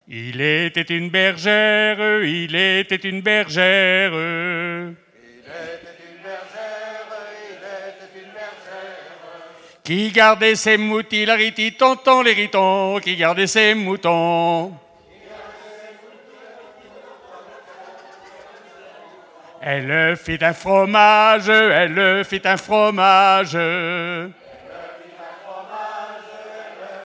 Festival de la chanson traditionnelle - chanteurs des cantons de Vendée
Pièce musicale inédite